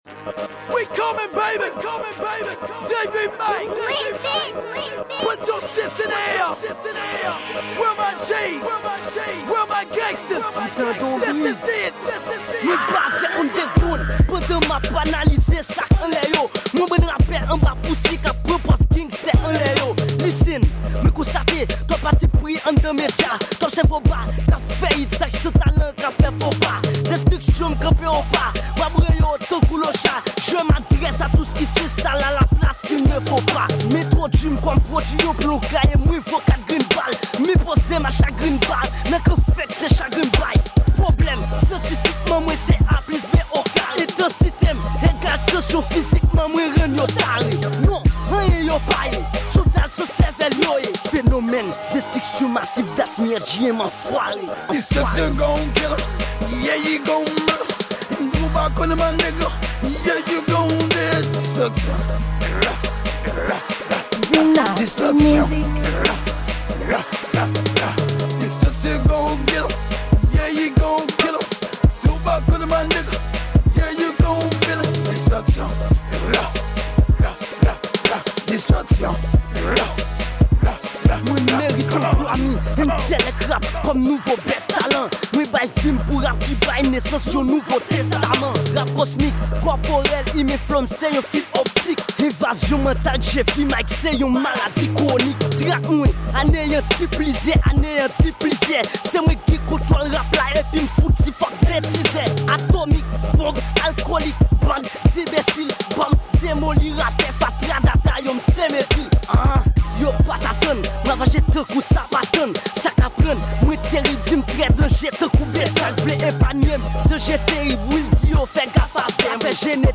Genre: HipHop/Rap Creole.